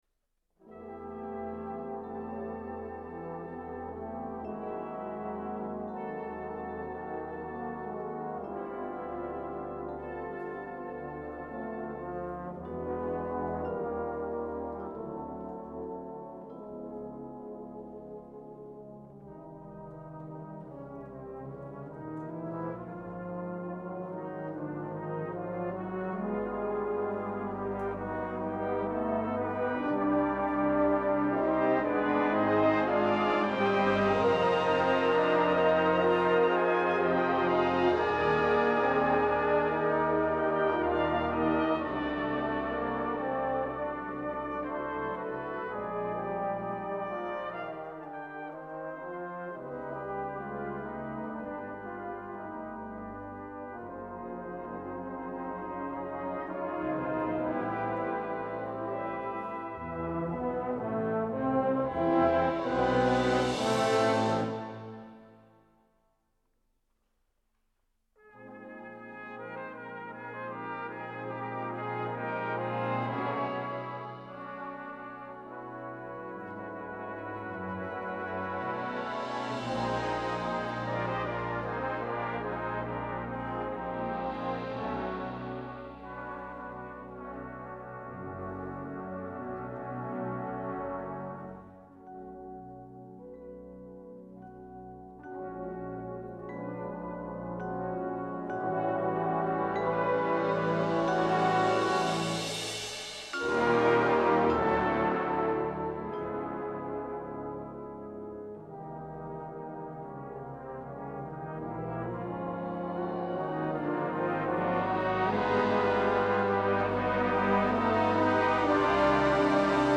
Brass Band version